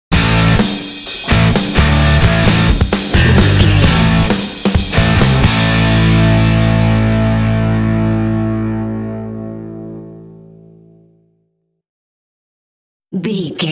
Epic / Action
Aeolian/Minor
energetic
heavy
electric guitar
drums
bass guitar
heavy metal
classic rock